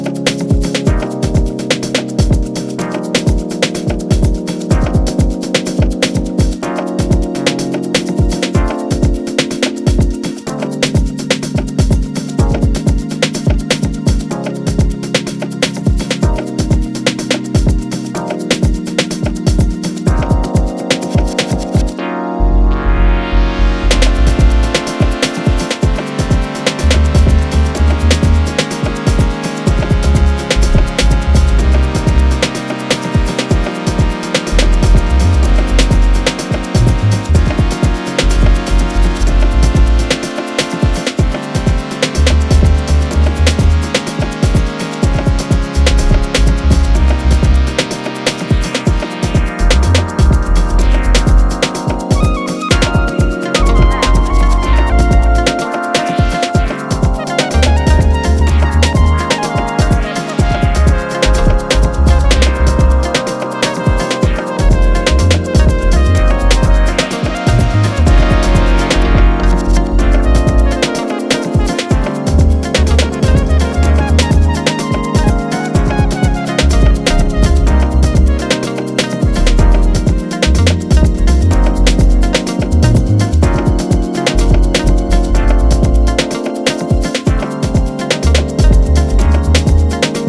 ディープ・ハウスの真髄を突いている、といっても過言では無いでしょう。